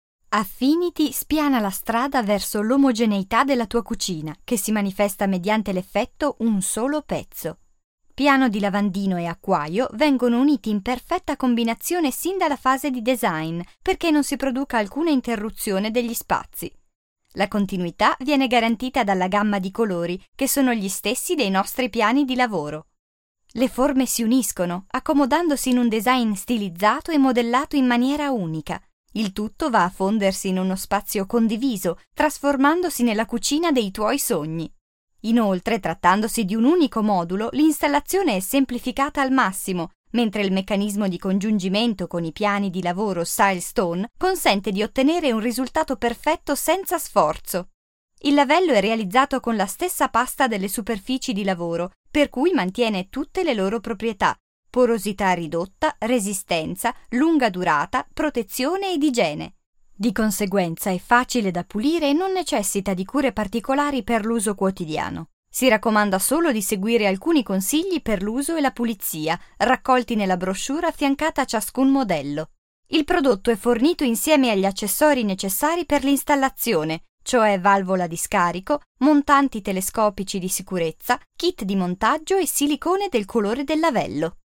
Kein Dialekt
Sprechprobe: Industrie (Muttersprache):
I am a speaker and dubber from Milan with a fresh, professional, extremely versatile kind of voice and, of course, perfect diction.